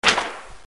Colpo di frusta
Rumore schiocco di frusta.
Effetto sonoro - Colpo di frusta